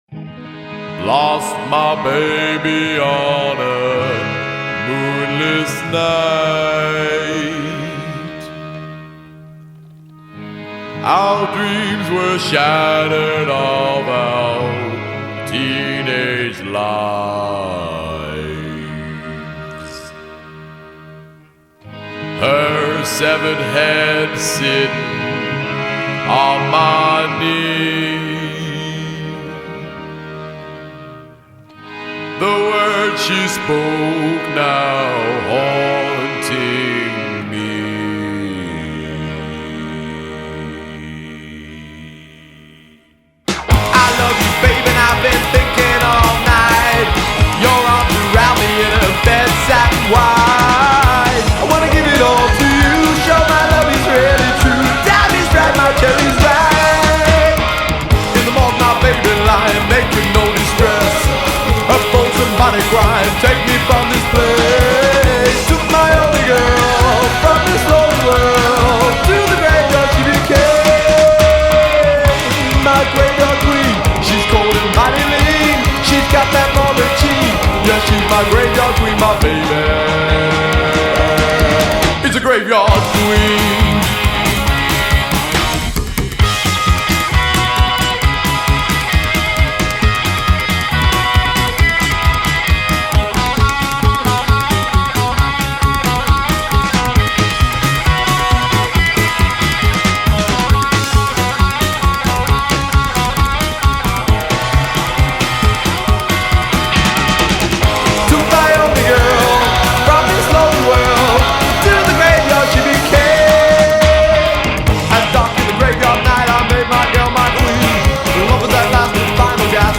their music is categorized as gothabilly and/or hellbilly.